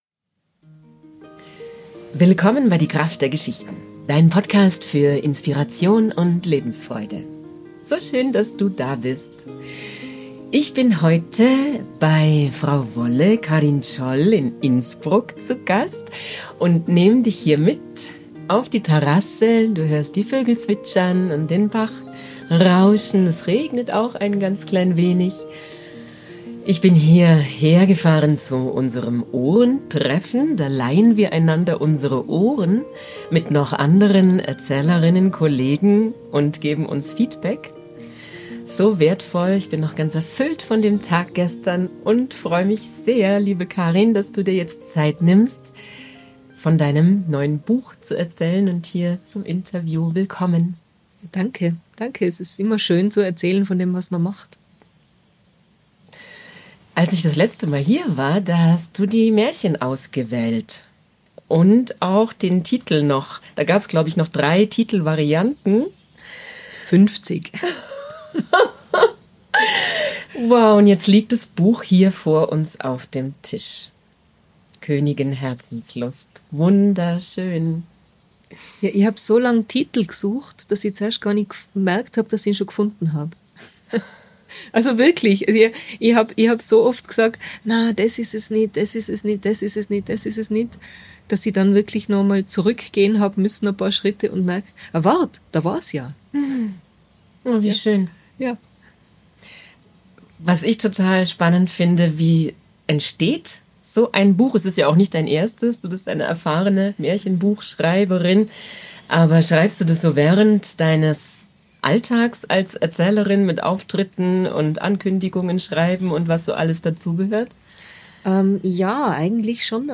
Heute bekommst Du nicht nur ein ganz wunderbares Interview, sondern auch ein Märchen erzählt.